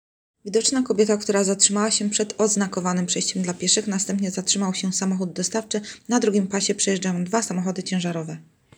Nagranie audio Audiodyskrypcja